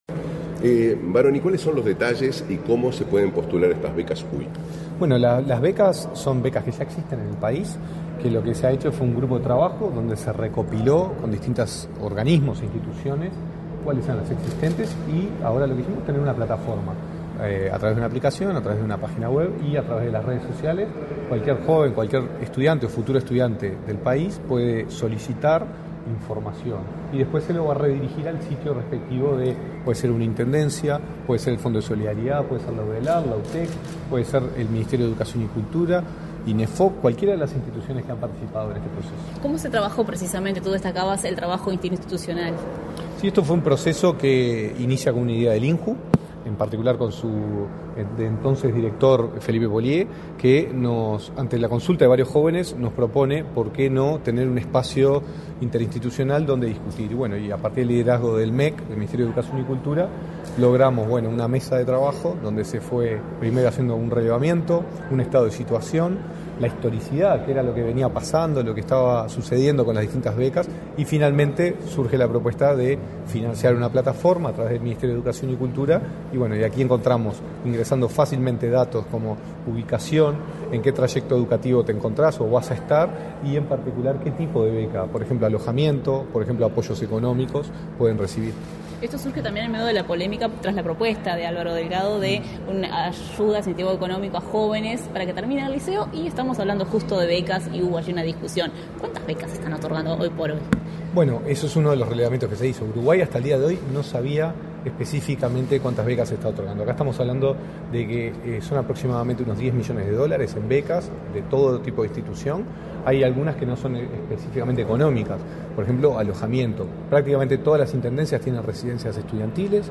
Declaraciones de prensa del director nacional de Educación, Gonzalo Baroni
La Dirección Nacional de Educación del Ministerio de Educación y Cultura (MEC) realizó, este 29 de agosto, el lanzamiento de Becas Uruguay (Becas UY), un sitio en línea que garantiza el acceso a la información sobre las becas de apoyo estudiantil de financiamiento público. Tras el evento, el director nacional de Educación, Gonzalo Baroni, realizó declaraciones a la prensa.